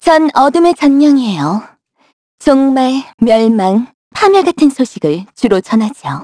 Xerah-vox-dia_01_kr.wav